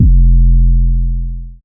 DDW6 808 8.wav